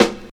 45 SNARE 5.wav